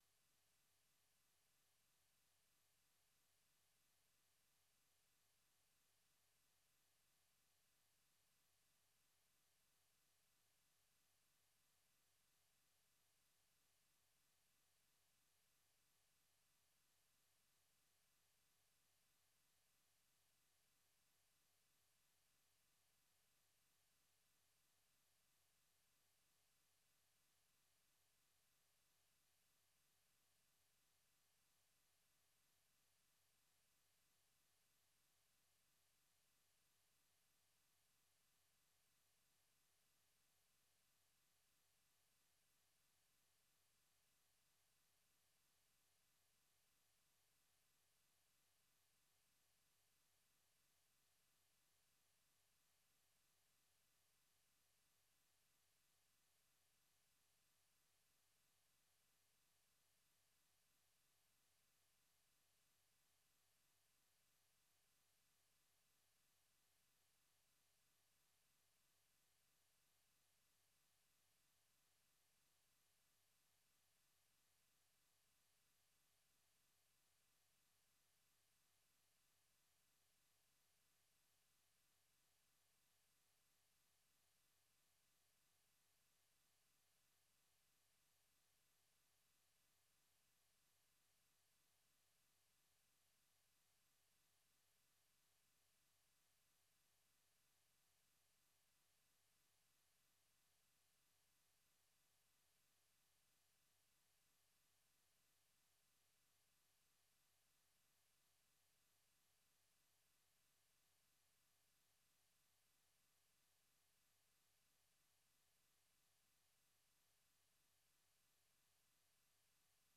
Idaacadda Galabnimo waxaad ku maqashaan wararka ugu danbeeya ee caalamka, barnaamijyo, ciyaaro, wareysiyo iyo waliba heeso.